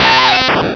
pokeemerald / sound / direct_sound_samples / cries / weedle.aif
-Replaced the Gen. 1 to 3 cries with BW2 rips.